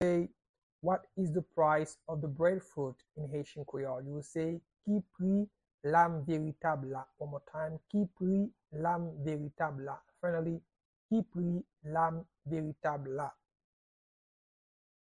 Pronunciation and Transcript:
How-to-say-What-is-the-price-of-the-breadfruit-in-Haitian-Creole-–-Ki-pri-lam-veritab-la-pronunciation.mp3